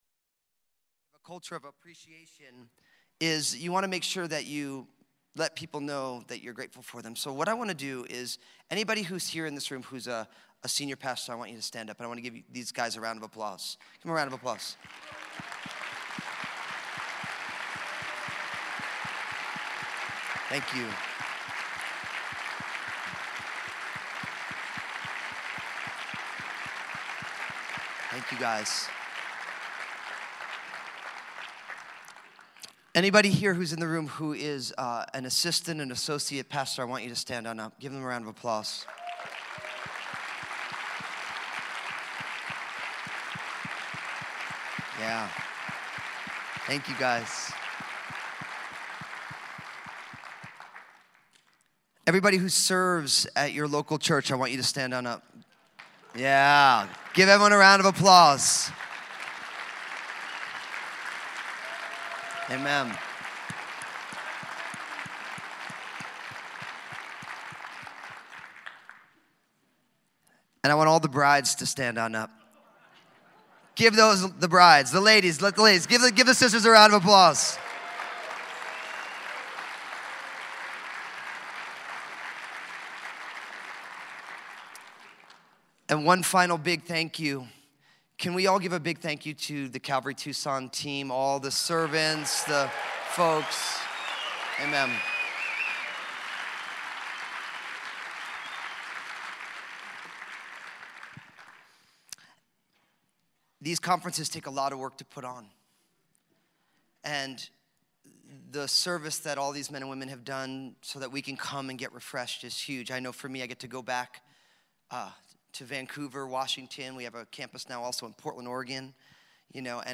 2017 SW Pastors and Leaders Conference, Seasons of a Shepherd